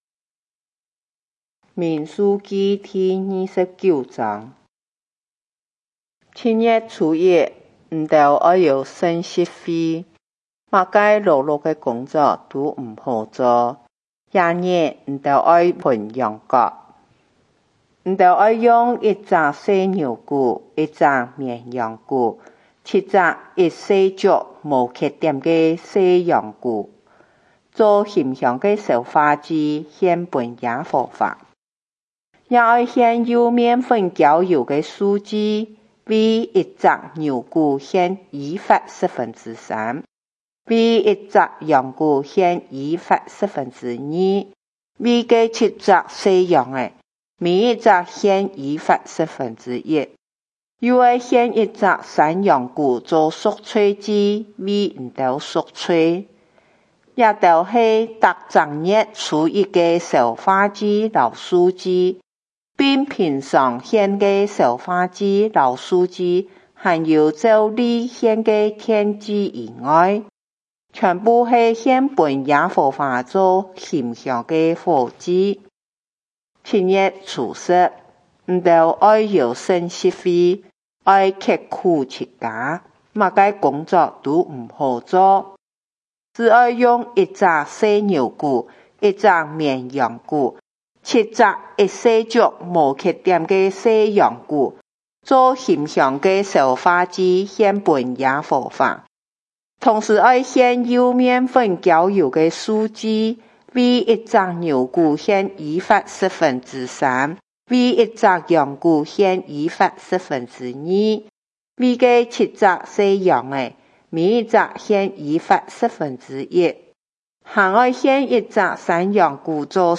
客家話有聲聖經 民數記 29章